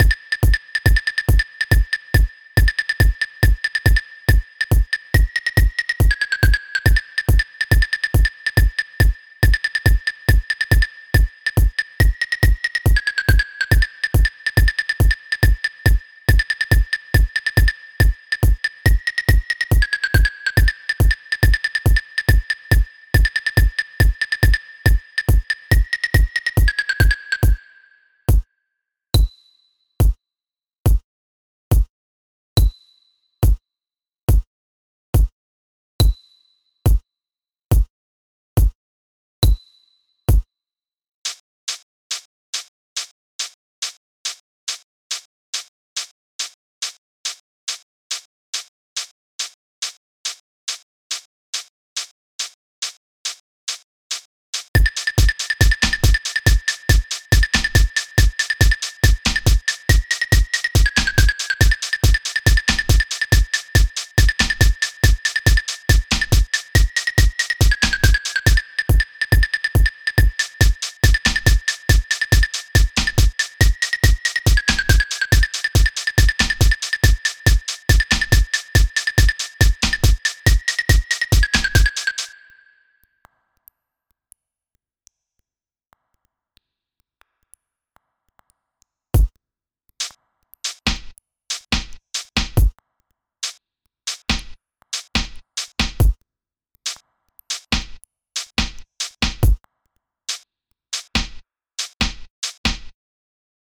weird, electronica, surreal, experimental,